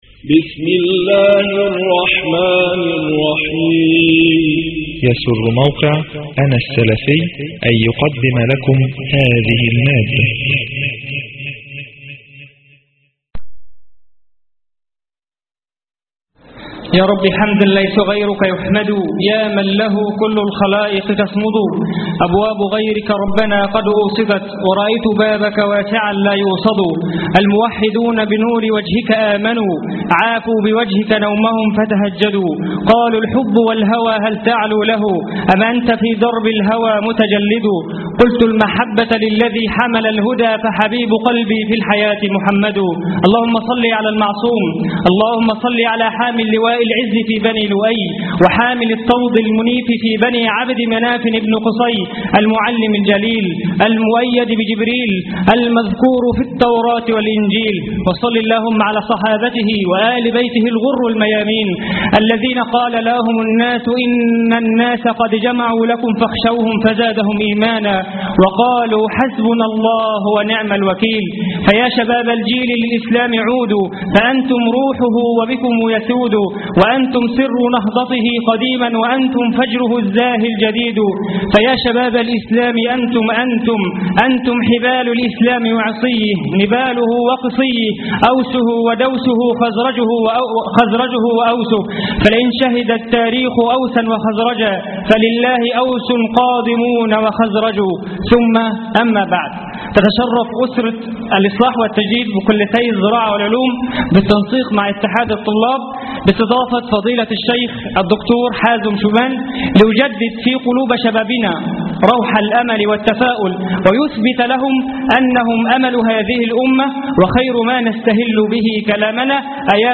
المؤتمر السلفي بكلية العلوم جامعة الأسكندرية